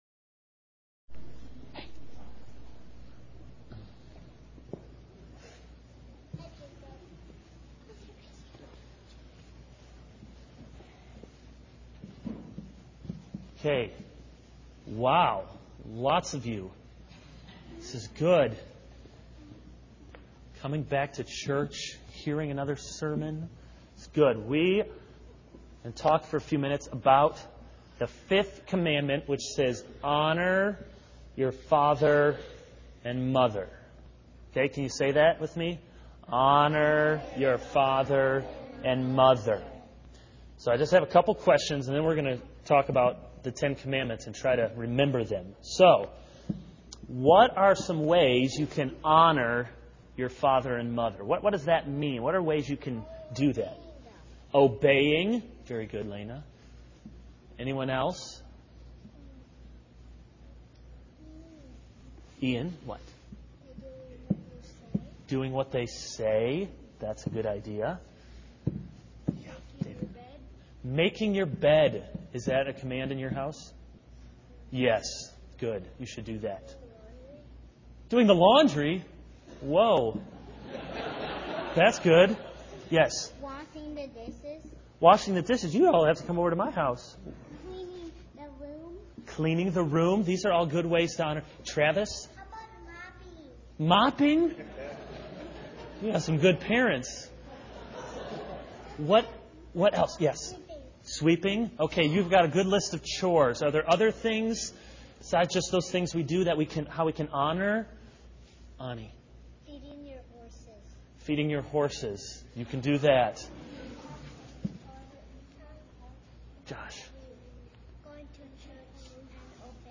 This is a sermon on Exodus 20:1-17 - Honor your father and mother.